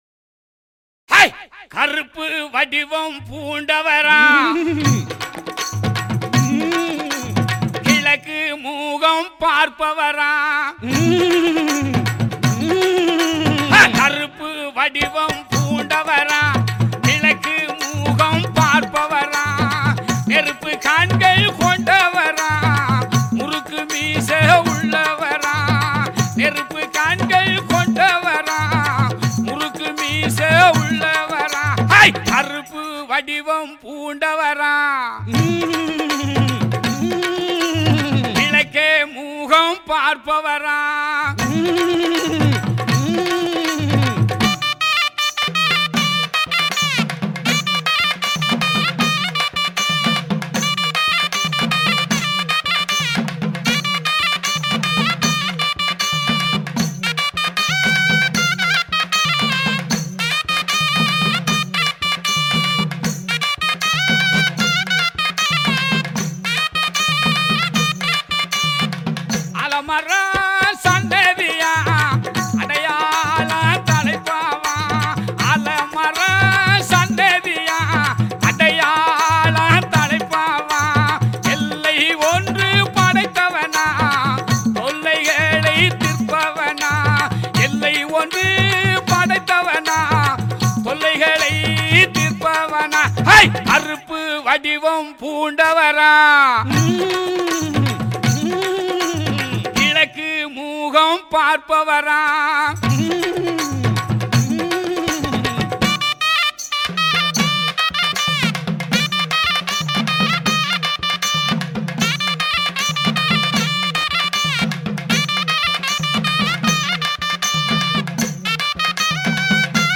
Tamil devotional album